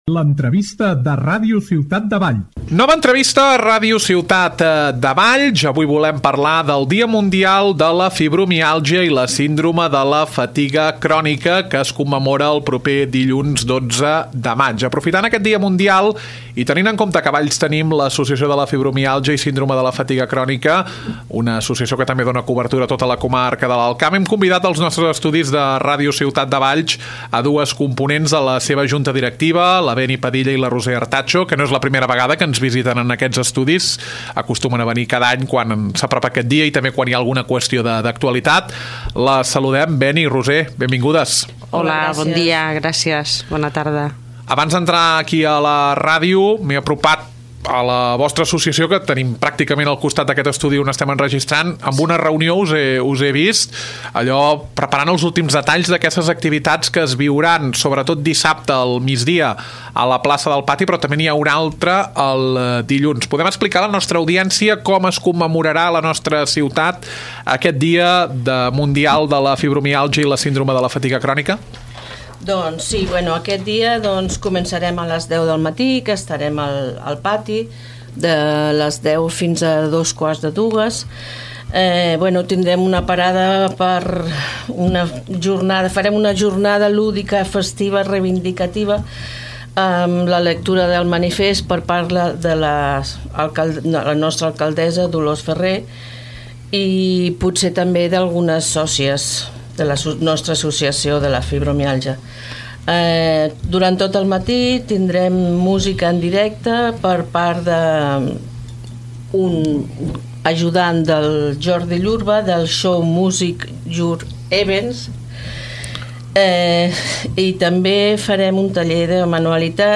Nova entrevista a Ràdio Ciutat de Valls.